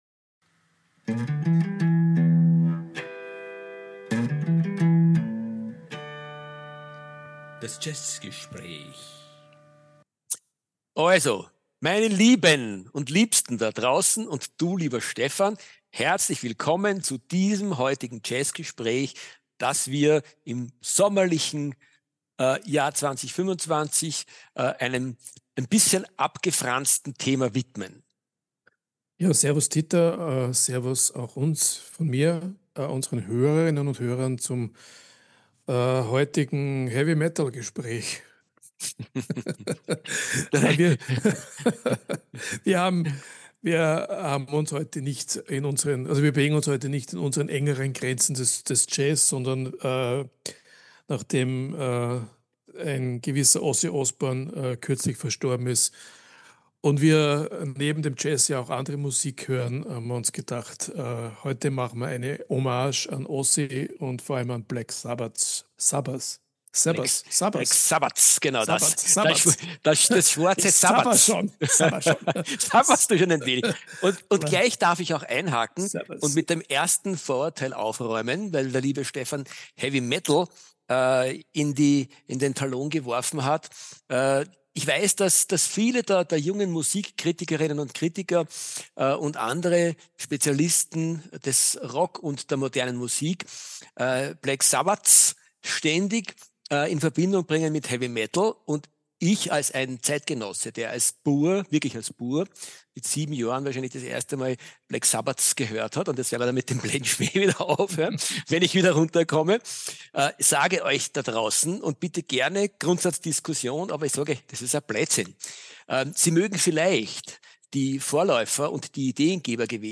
Letzte Episode Folge 93: Dead Sabbath 14. September 2025 Nächste Episode download Beschreibung Teilen Abonnieren Anlässlich des kürzlich verstorbenen "Prince of Darkness" Ozzy Osbourne verlassen wir unseren geliebten Jazz für diese Folge und wenden uns der Geschichte von Black Sabbath zu, erzählt von einem großen und einem nicht ganz so großen Fan dieser Band.